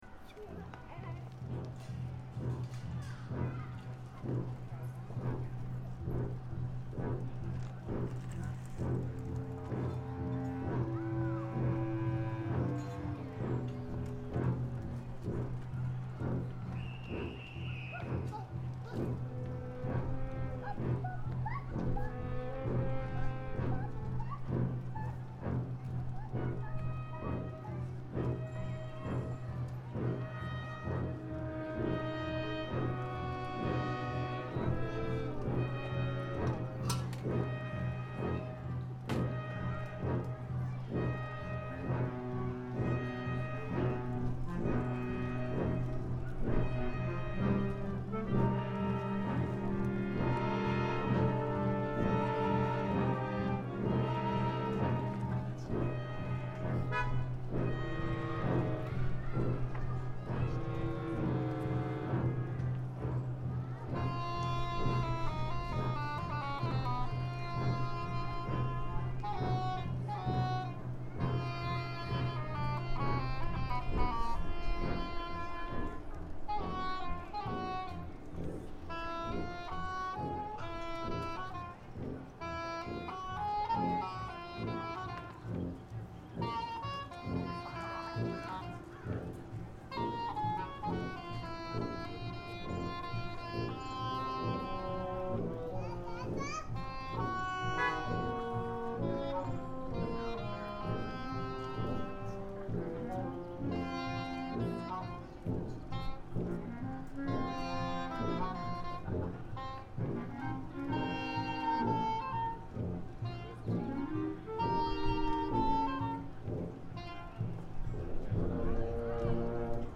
Listing of music recorded by Long Beach Community Band groups.